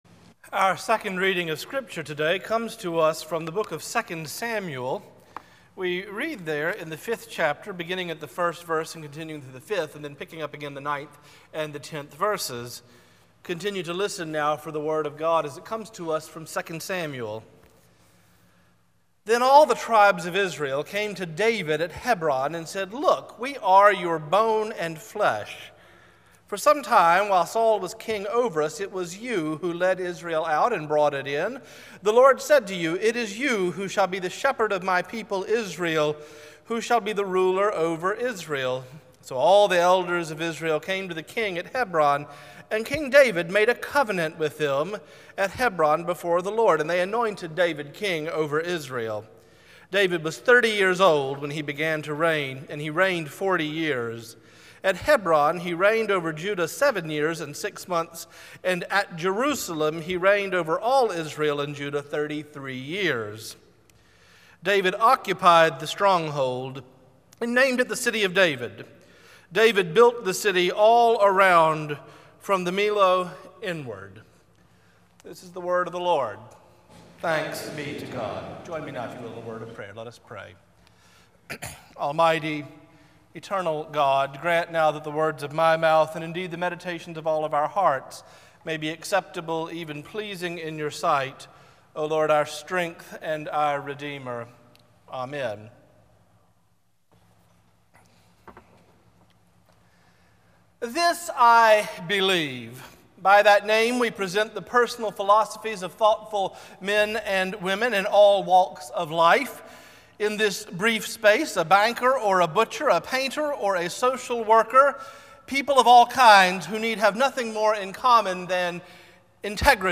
Morningside Presbyterian Church - Atlanta, GA: Sermons: The City of God